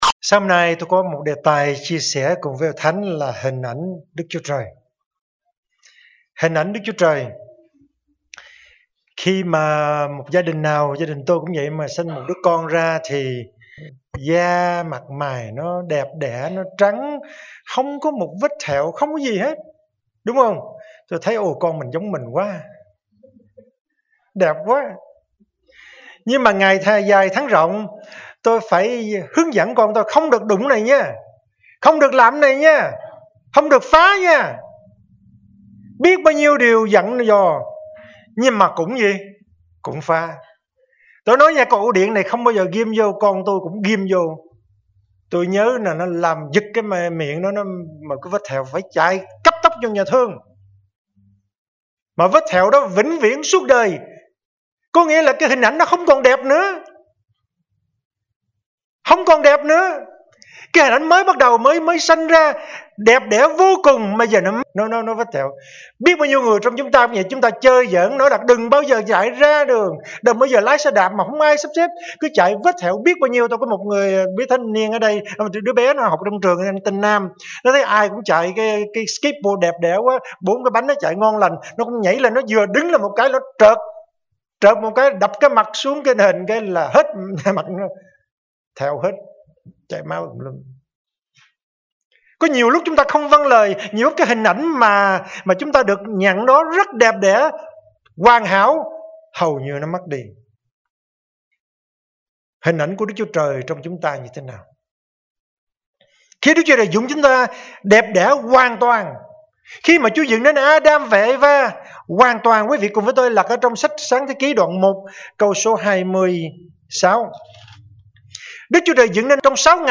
Sermon / Bài Giảng